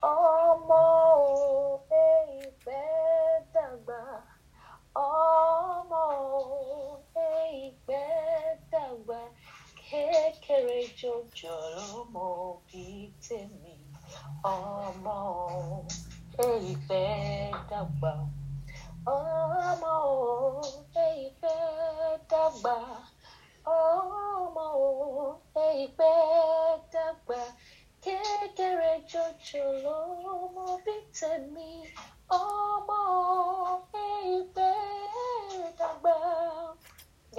Yoruba song about childhood/children.